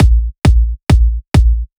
VTS1 Lovely Day Kit Drums & Perc